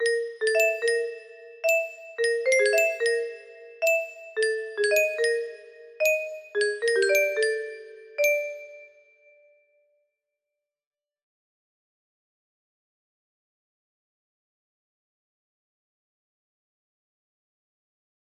Untitled music box melody